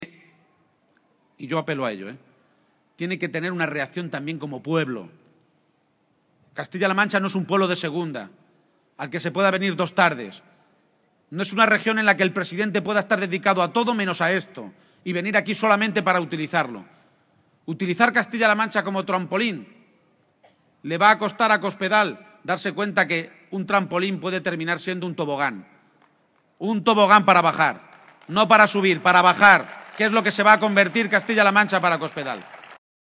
Audio Page en Villarrobledo 4